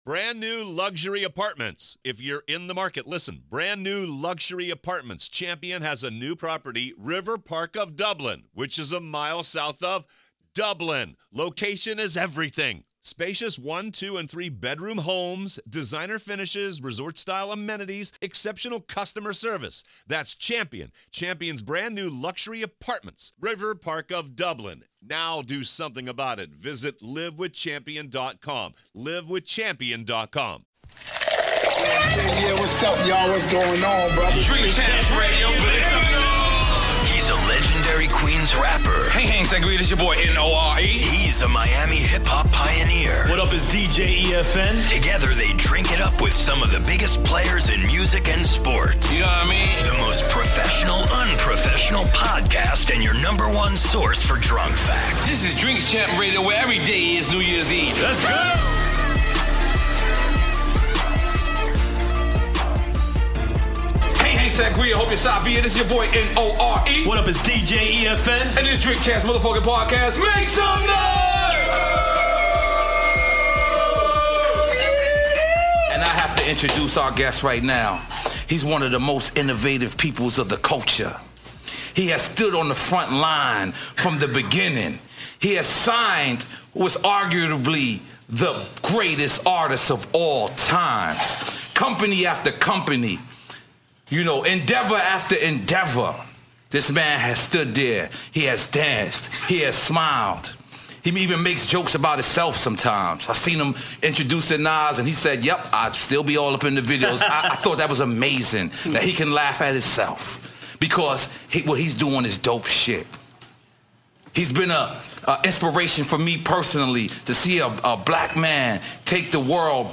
N.O.R.E. and DJ EFN are the Drink Champs. In this monumental episode the guys drink it up with iconic hip hop mogul Puff Daddy. The guys talk untold Biggie stories, Puff's brands and career and just overall some Drink Champs fun.